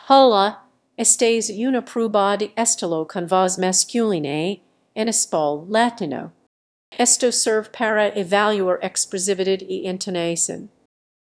dub_male_style_out.wav